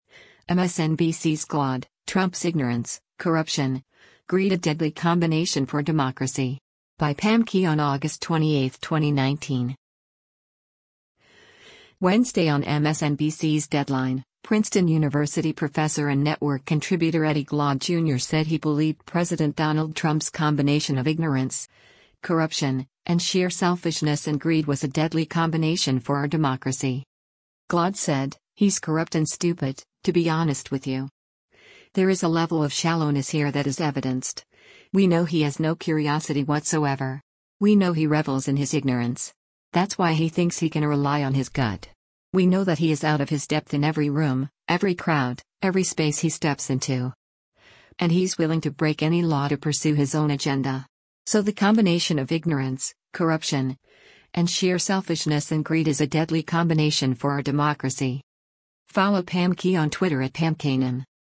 Wednesday on MSNBC’s “Deadline,” Princeton University professor and network contributor Eddie Glaude, Jr. said he believed President Donald Trump’s “combination of ignorance, corruption, and sheer selfishness and greed” was a “deadly combination” for our democracy.